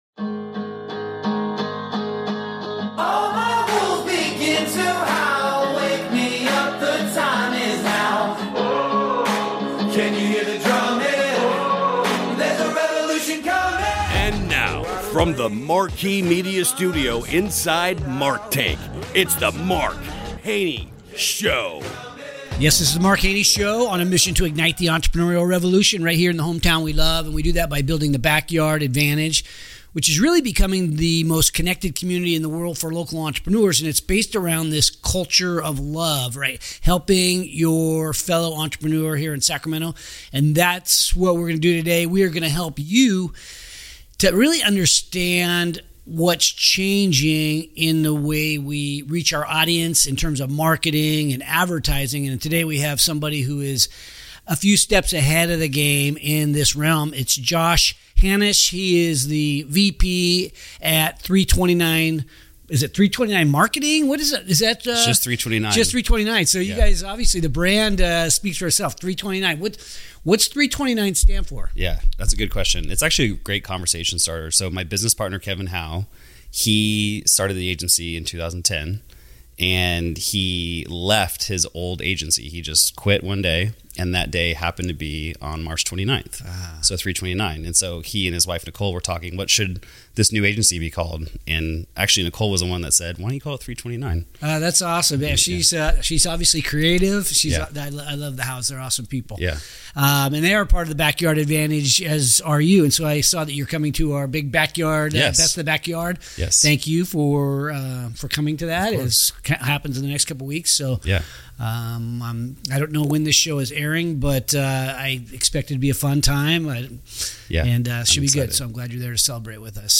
Subscribe for more conversations with the people building the future of entrepreneurship and innovation.